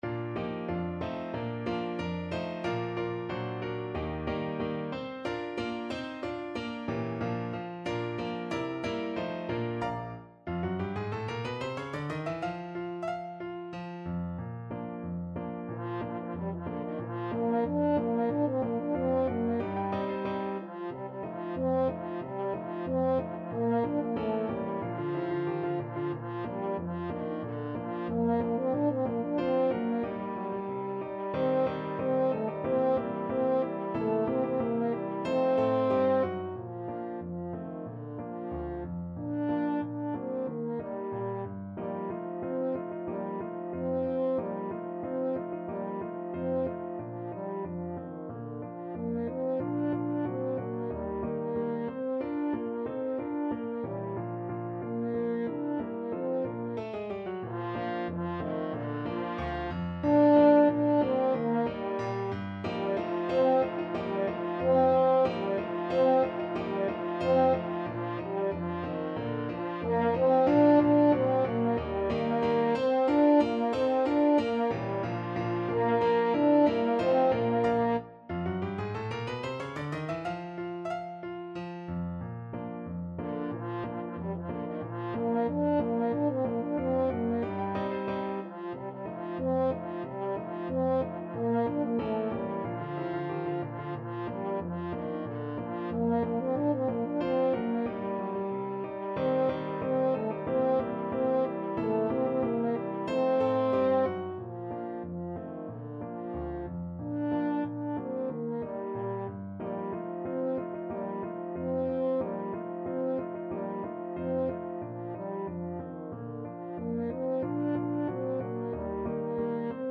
Allegretto =92
2/4 (View more 2/4 Music)
Traditional (View more Traditional French Horn Music)
Rock and pop (View more Rock and pop French Horn Music)